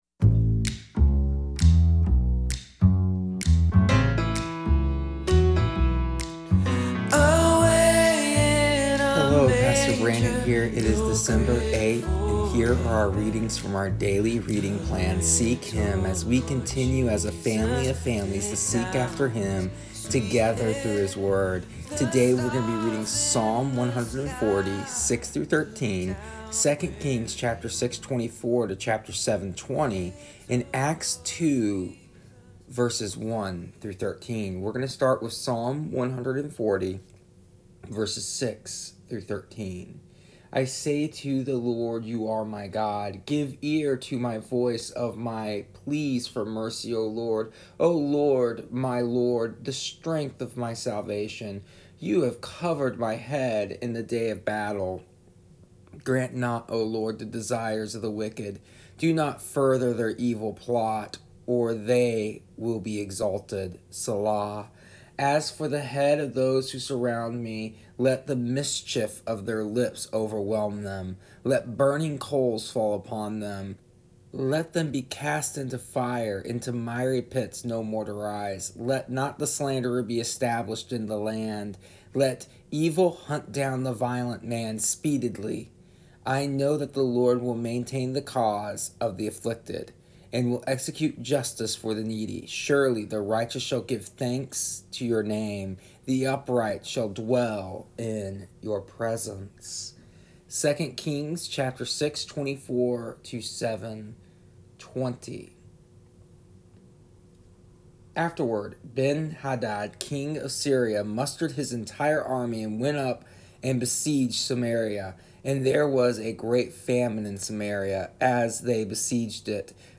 Here is the audio version of our daily readings from our daily reading plan Seek Him for December 8th, 2020. Today we read the story of the day of Pentecost and the coming of the Holy Spirit empowering the Church in just the moment it needed to be empowered, with just the right way it needed to be empowered.